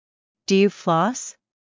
ﾄﾞｩ ﾕｰ ﾌﾛｽ